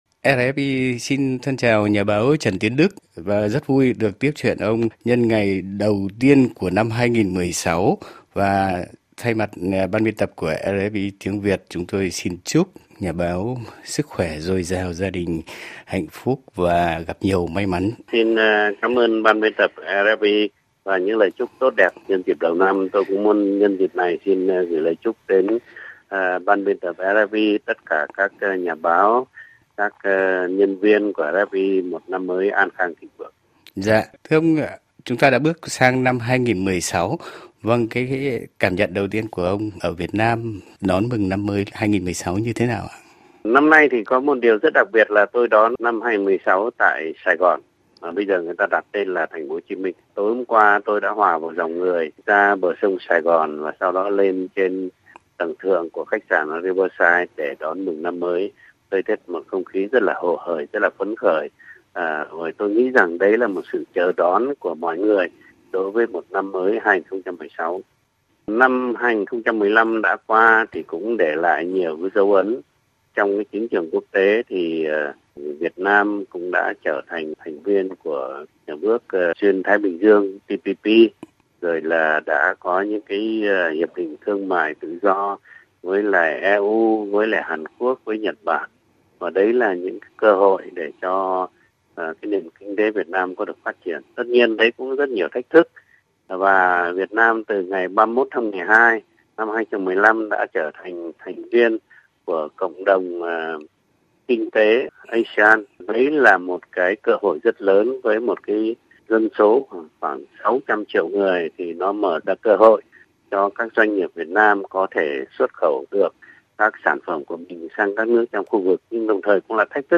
qua điện thoại viễn liên đã dành cho RFI cuộc phỏng vấn đầu năm để nói về những quan sát